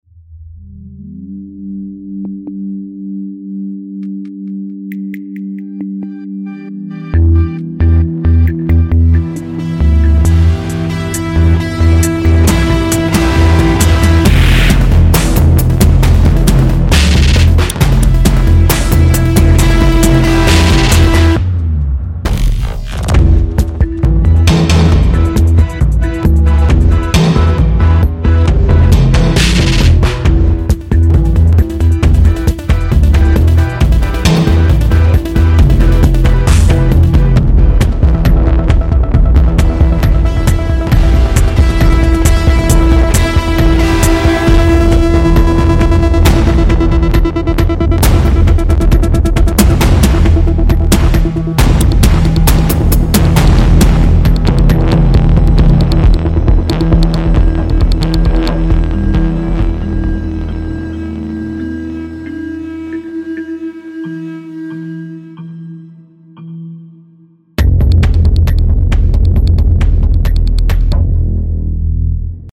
它提供了超过 300 种独特的鼓、打击和效果声源，以及 528 种预设，让您可以轻松地创建各种风格的节奏和律动。
总之， Rytmik 2 是一款功能强大而灵活的打击乐器，能够为您的音乐创作带来有机的电影风格和电子打击元素。